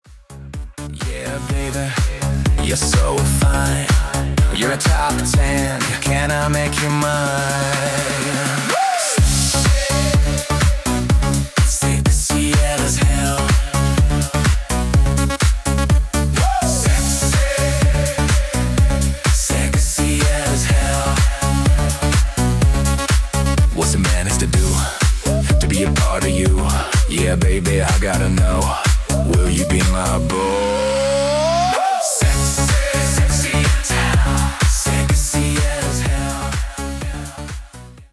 Deep bass club dance track vibe.